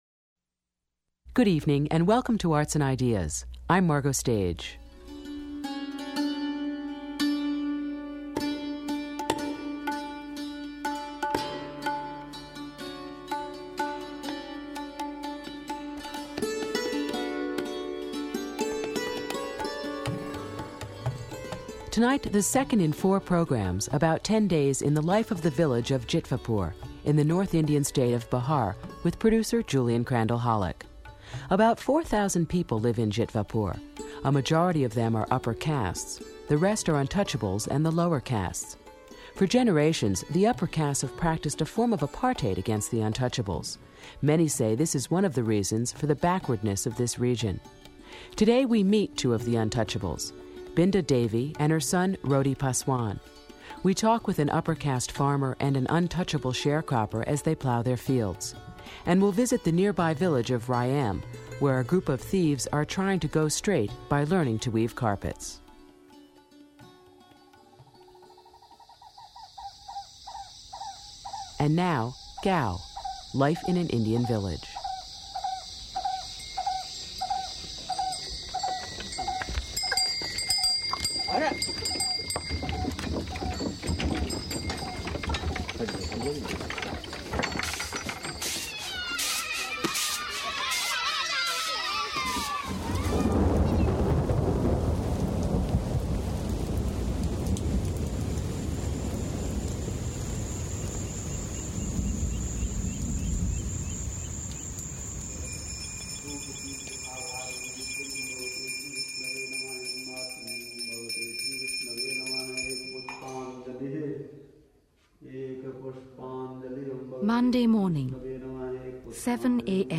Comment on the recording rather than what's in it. Monday morning, 7 AM at the Kali Temple in the mango grove 3.